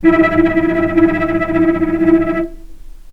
healing-soundscapes/Sound Banks/HSS_OP_Pack/Strings/cello/tremolo/vc_trm-E4-pp.aif at ae2f2fe41e2fc4dd57af0702df0fa403f34382e7
vc_trm-E4-pp.aif